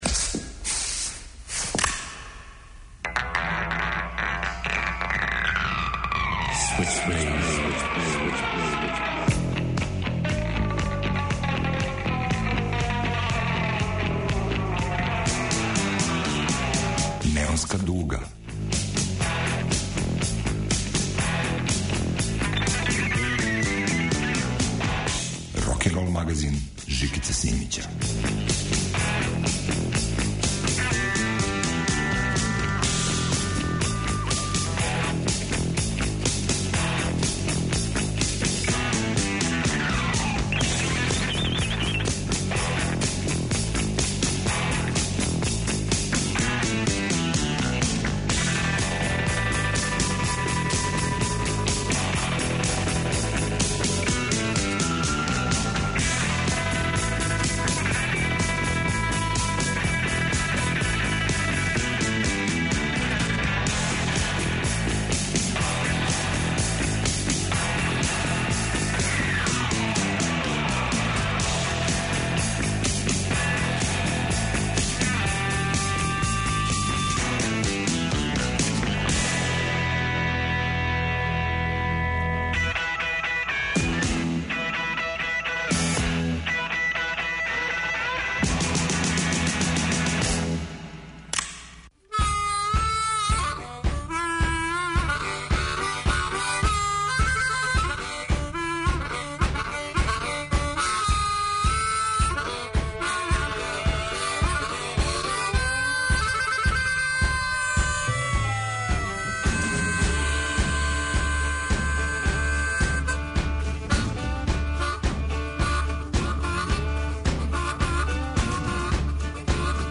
Усне хармонике завијају као вукови на месец у новом издању 'Неонске дуге'.
Рокенрол као музички скор за живот на дивљој страни. Вратоломни сурф кроз време и жанрове.